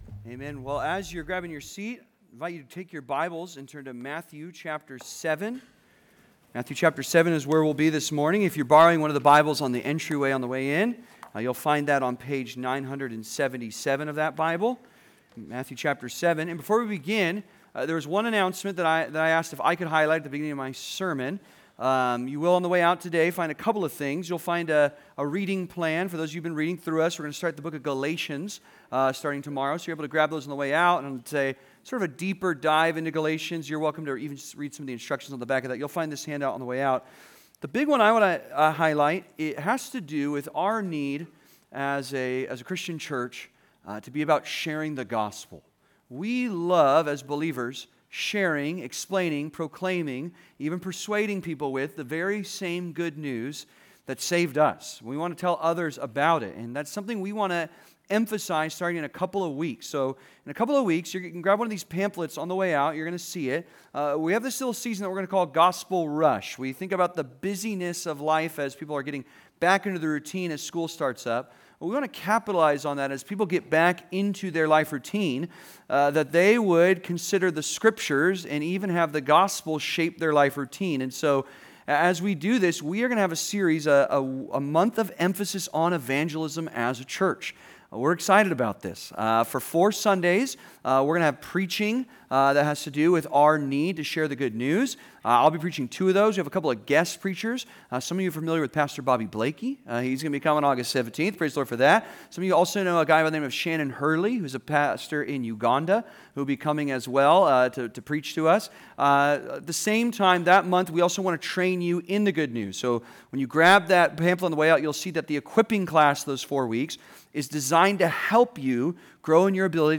Two Ways to Live (Sermon) - Compass Bible Church Long Beach